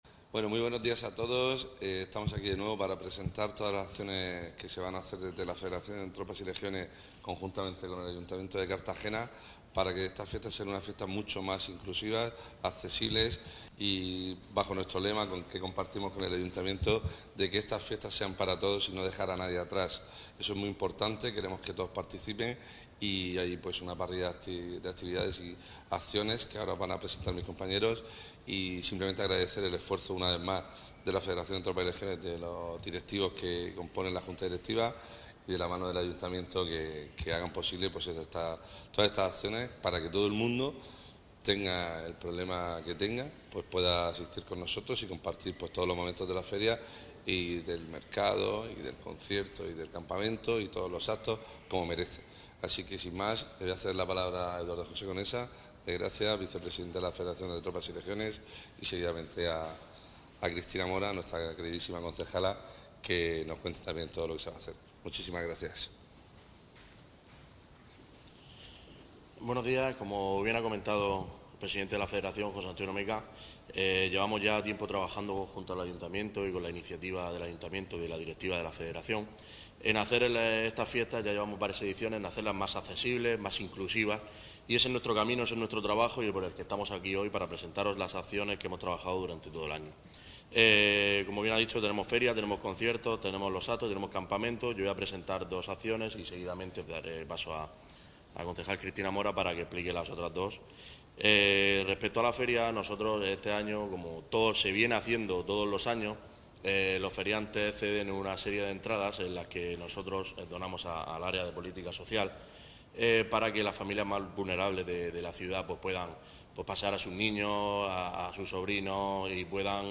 El Palacio Consistorial ha acogido la presentación de las diferentes acciones que se llevarán a cabo para que todos los colectivos puedan participar en estos días de celebración que, contará con traducción de lengua de signos en dos de los grandes momentos de las fiestas, el Pregón y el Apagado del Fuego Sagrado, según ha anunciado la concejala del área de Política Social, Familia e Igualdad, Cristina Mora.